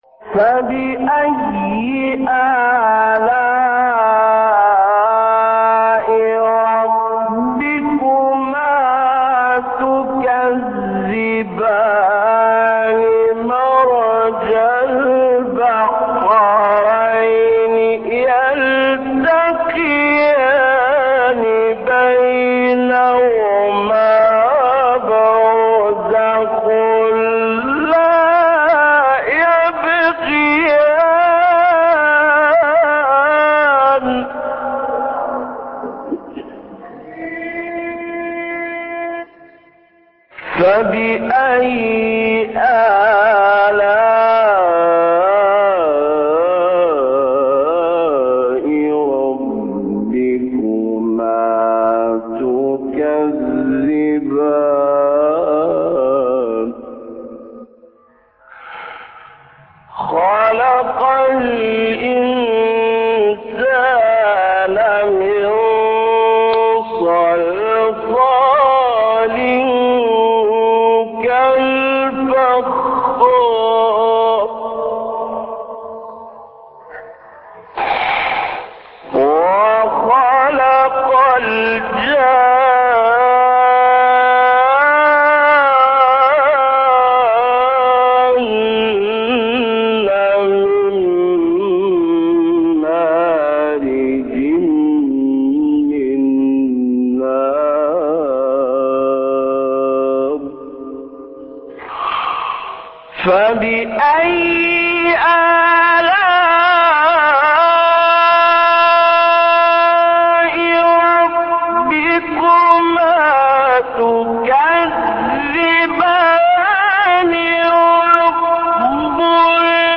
سورة الرحمن ـ الليثي ـ مقام النهاوند - لحفظ الملف في مجلد خاص اضغط بالزر الأيمن هنا ثم اختر (حفظ الهدف باسم - Save Target As) واختر المكان المناسب